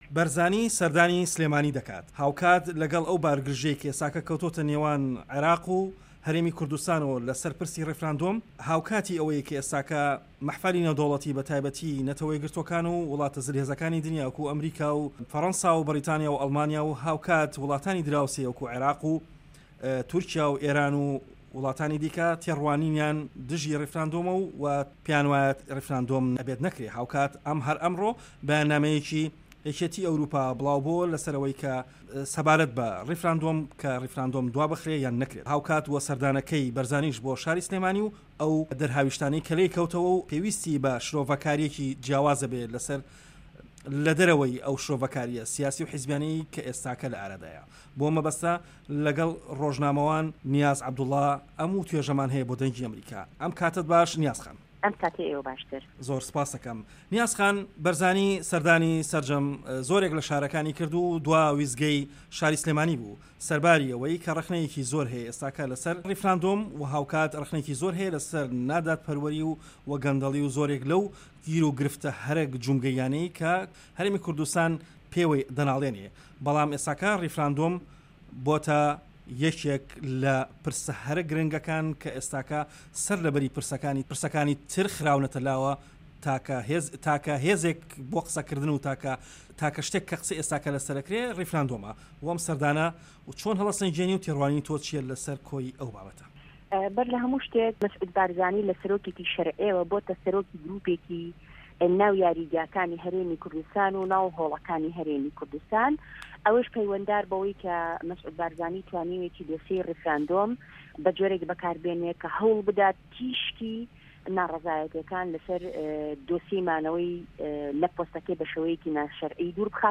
وتووێژ لەگەڵ نیاز عەبدوڵڵا